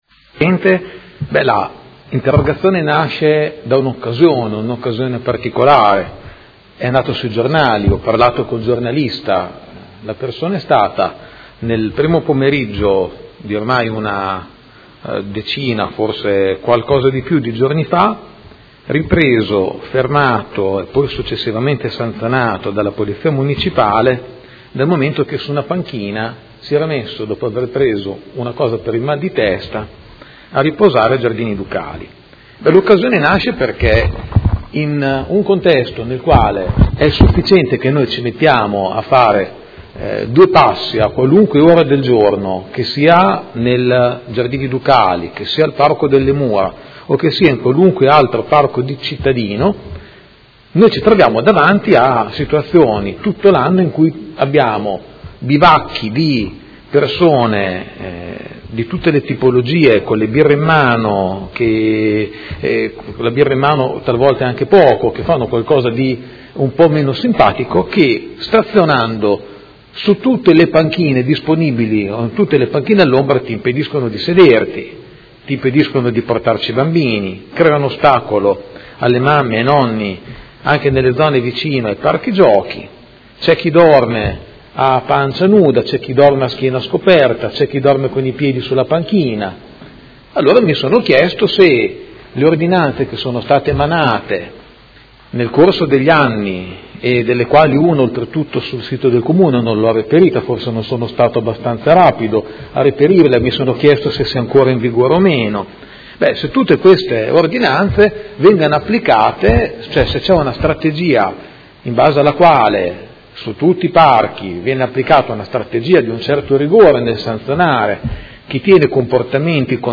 Seduta del 26/06/2017. Interrogazione del Consigliere Pellacani (FI) avente per oggetto: Quante contravvenzioni sono state elevate ai Giardini Ducali e negli altri parchi di Modena per violazioni alle norme anti alcool e antidegrado?